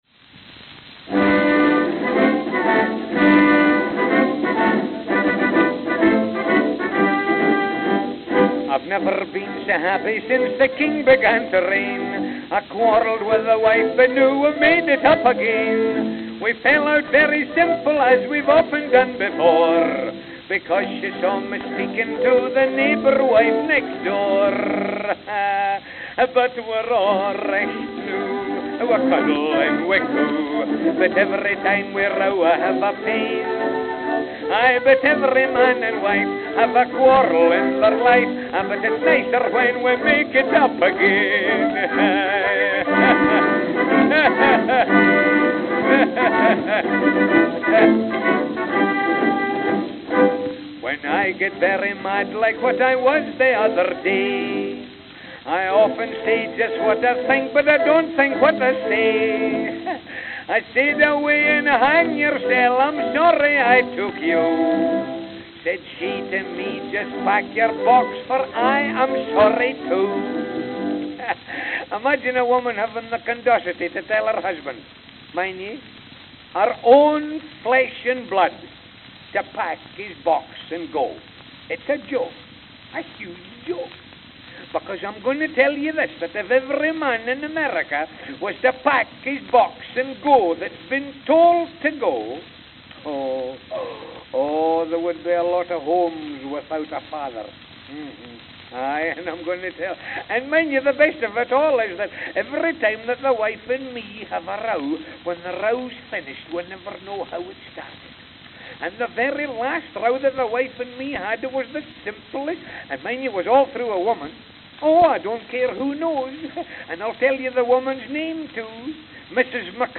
November 20, 1915 (New York, New York) (4/4)